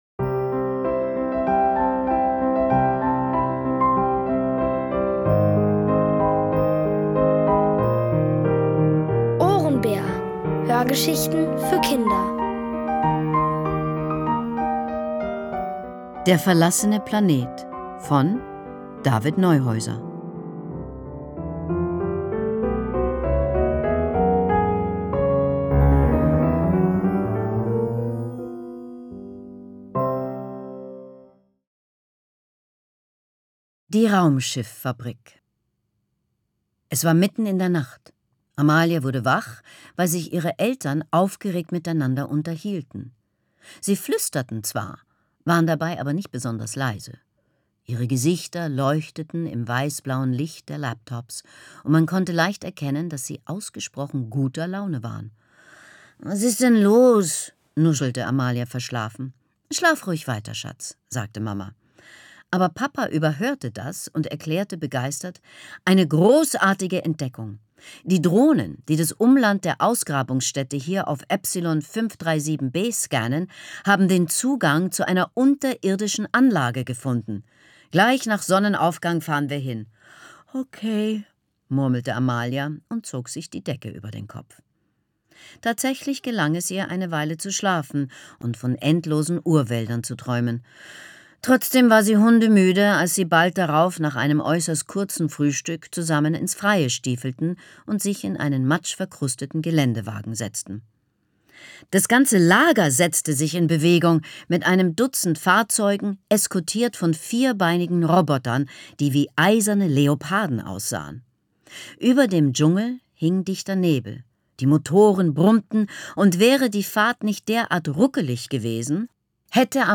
Von Autoren extra für die Reihe geschrieben und von bekannten Schauspielern gelesen.
Es liest: Leslie Malton.